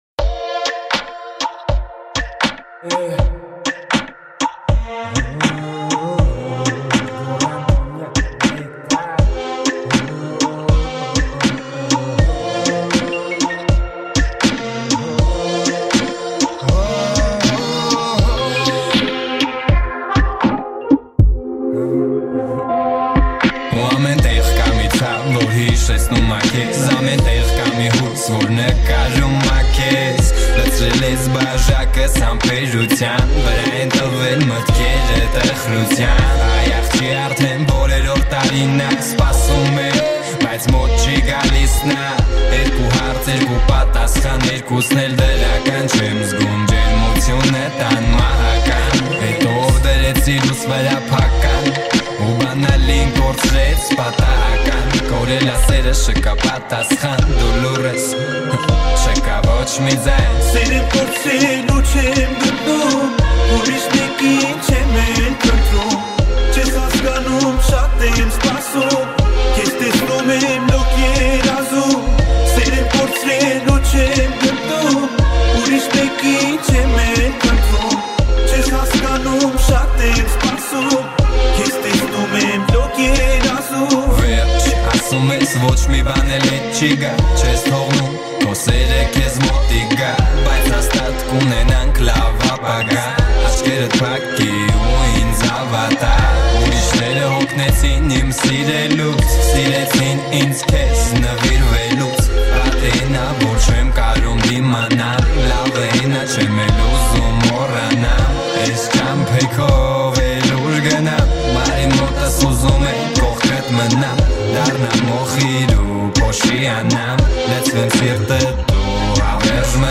Армянская музыка, Erger 2018, Дуэт